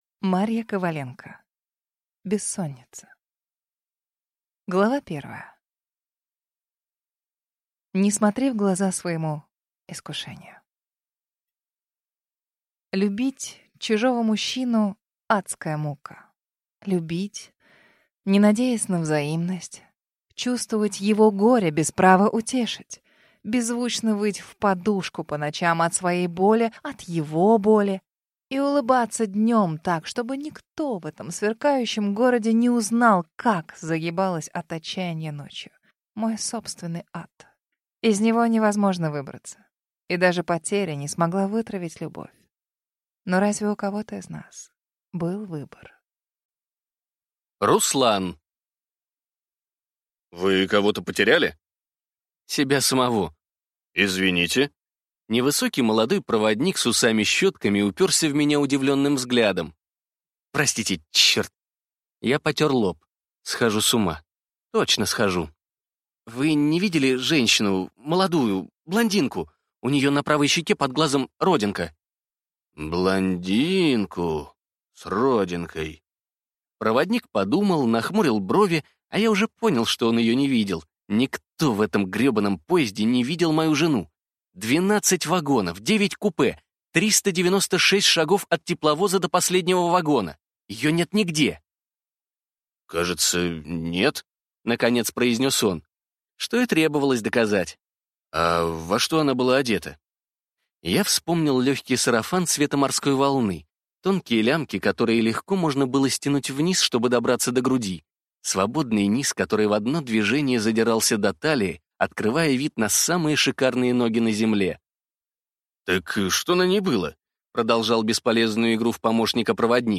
Аудиокнига Бессонница | Библиотека аудиокниг